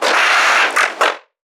NPC_Creatures_Vocalisations_Infected [22].wav